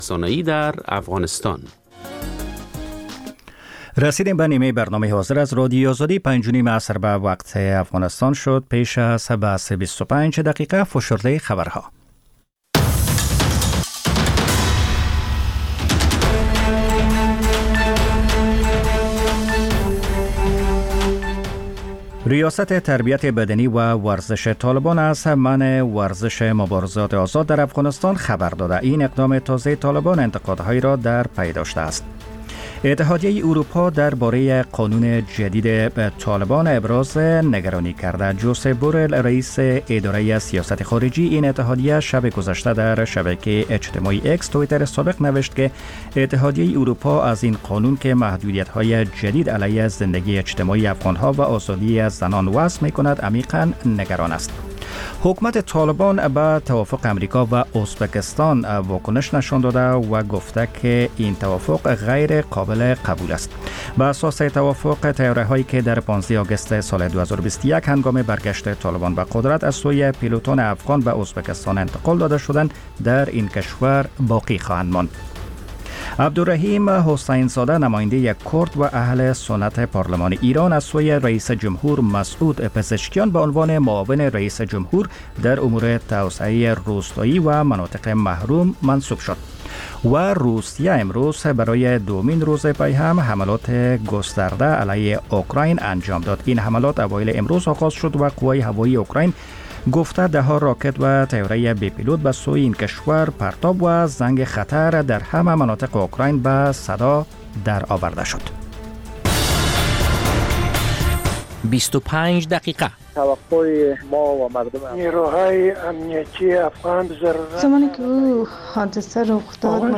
خبرهای کوتاه - میز گرد (تکرار)